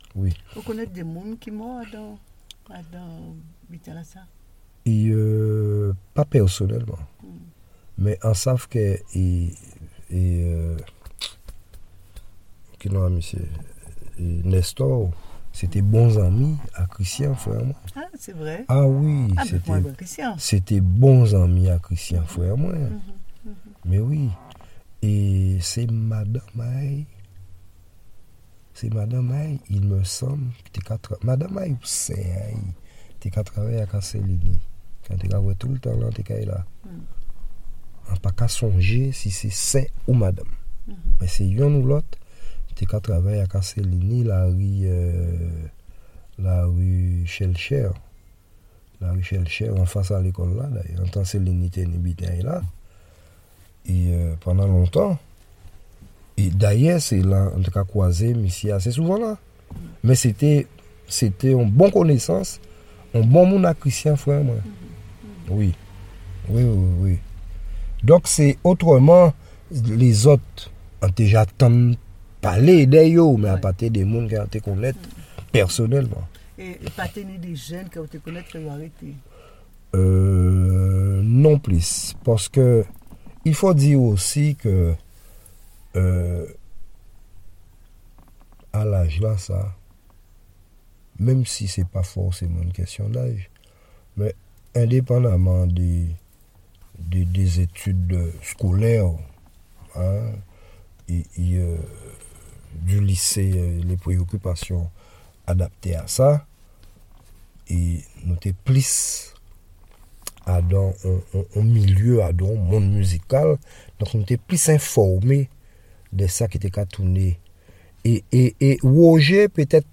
Intégralité de l'interview.